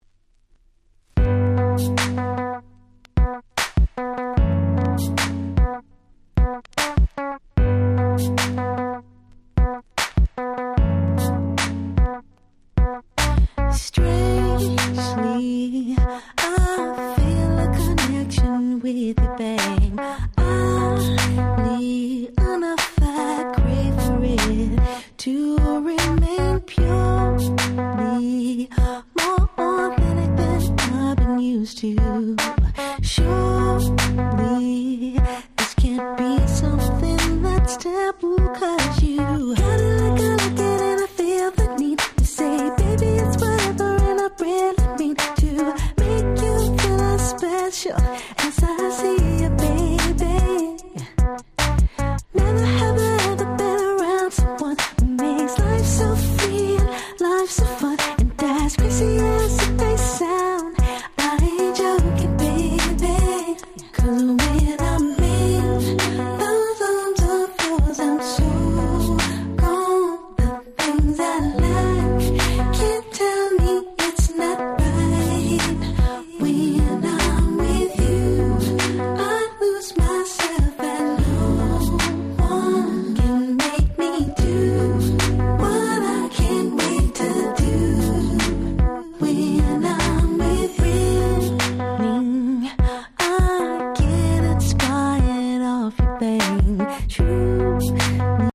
07’ Nice R&B !!
そこまでヒットしなかったものの美メロですごく良い1曲！！